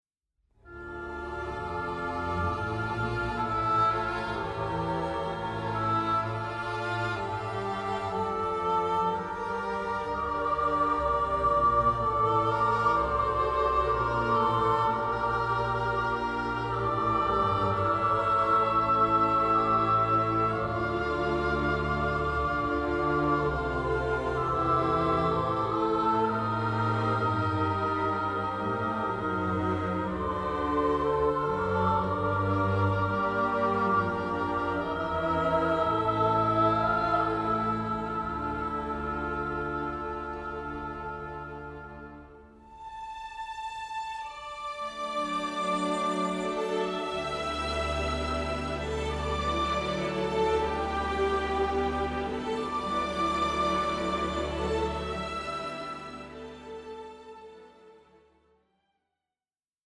full of heartfelt themes, lilting waltzes, Indian flourishes